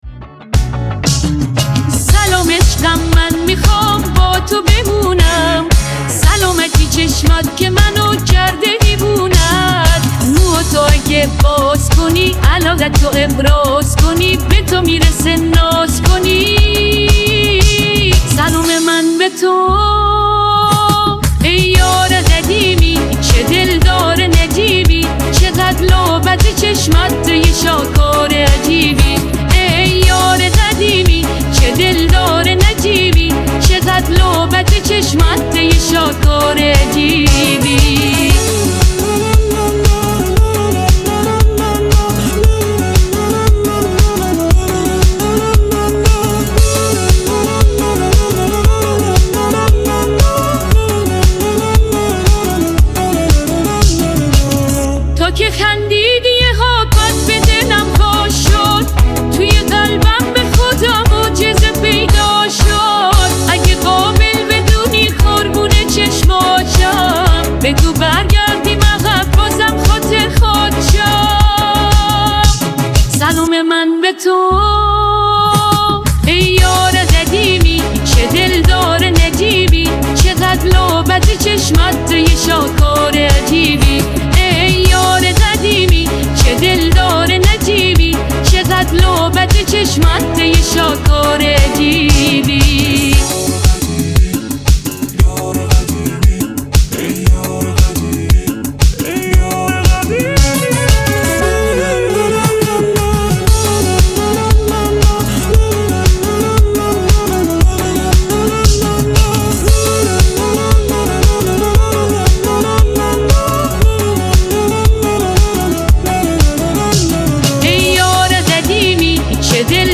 ژانر: پاپ / رپ